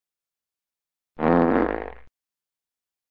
fart